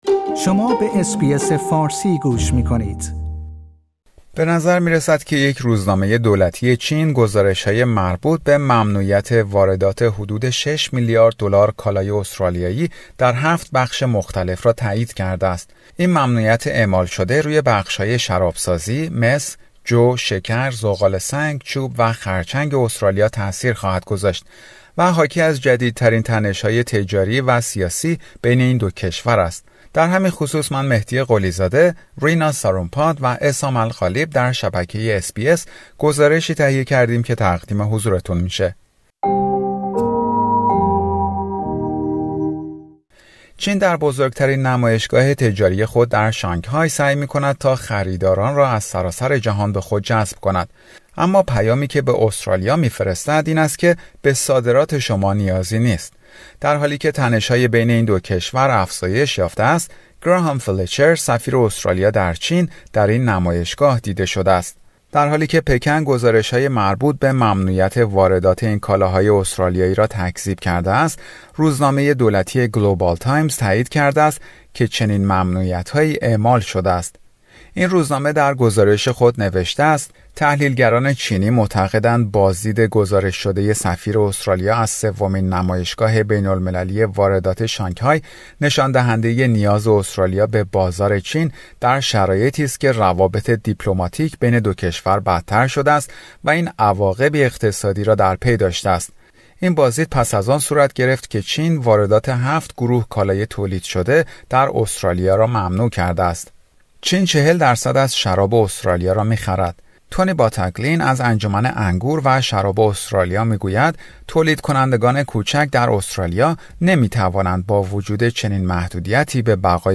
گزارشی در مورد ممنوعیت واردات ۶ میلیارد دلار کالای استرالیایی از سوی چین